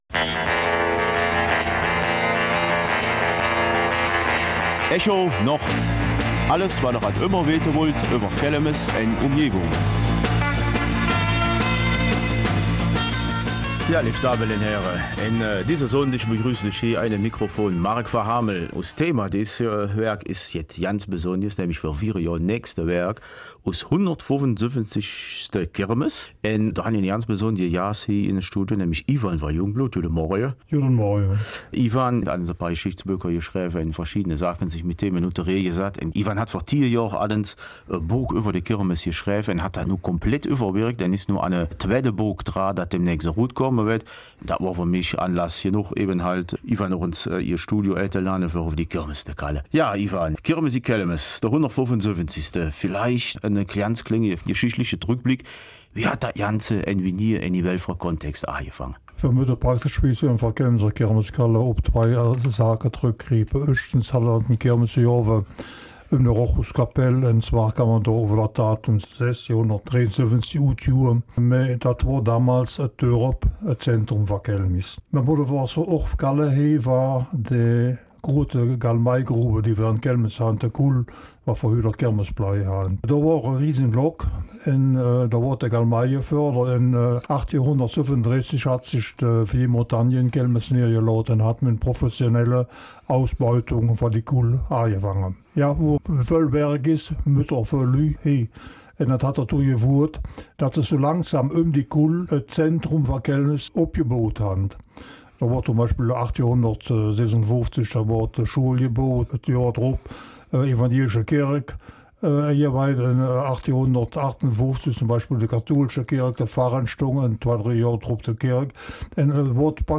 Kelmiser Mundart